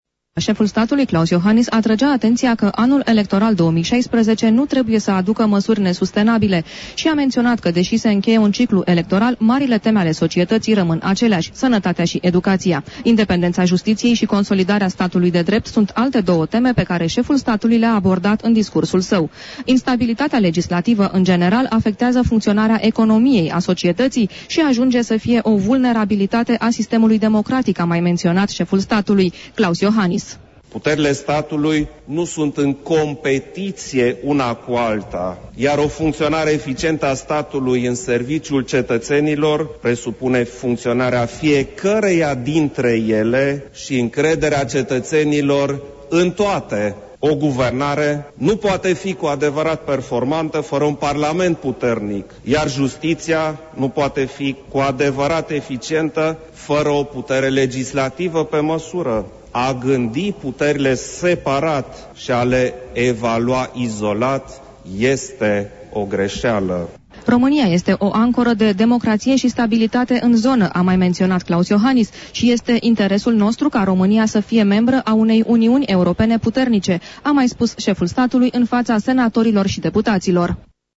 Ne oferă detalii reporterul RRA